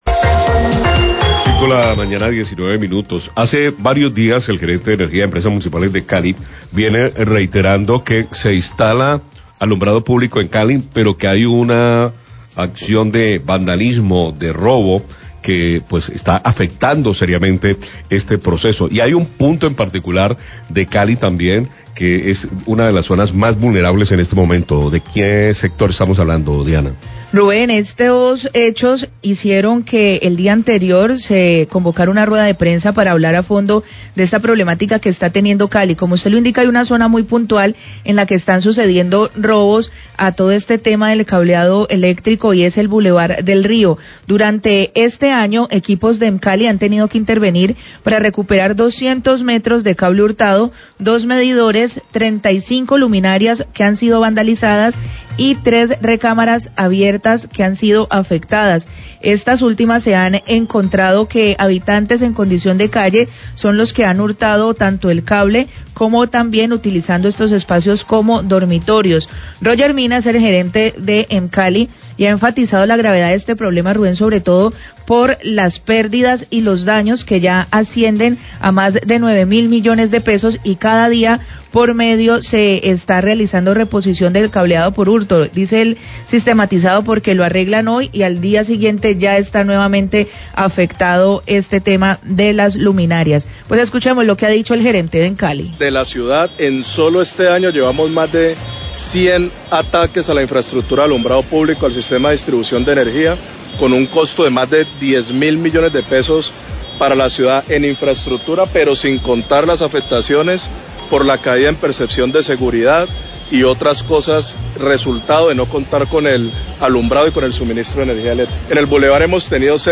Radio
El gerente de Emcali, Roger Mina, denuncia el hurto de cable y el vandalismo contra la infrestructura ele´ctrica del Blevar del Río. Por su parte, el Subsecretario de Seguridad. Álvaro Pretelt, explica las acciones que se adelantan para garantizar la seguridad del lugar.